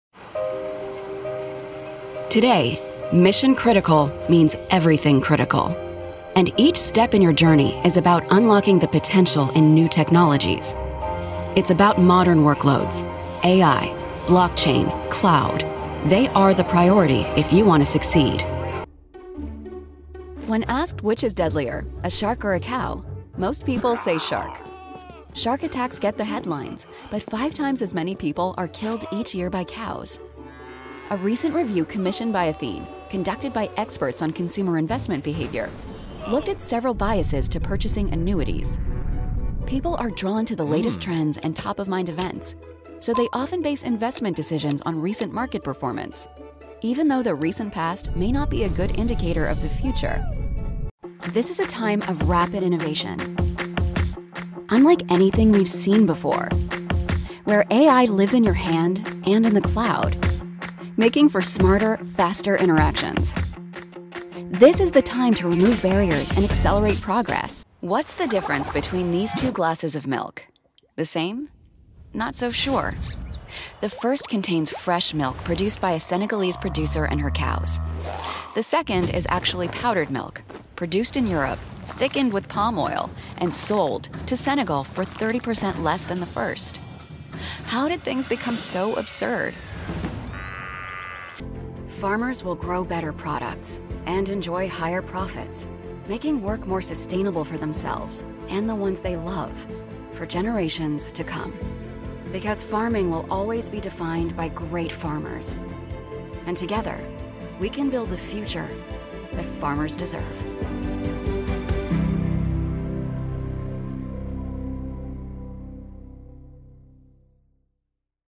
From witty and wry to warm and compassionate, I've got you.
Corporate Demo